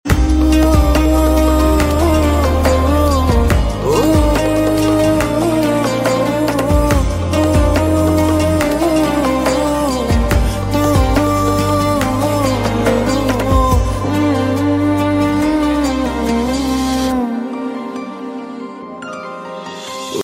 BGM
Heart touching Melody